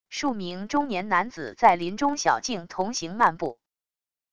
数名中年男子在林中小径同行漫步wav音频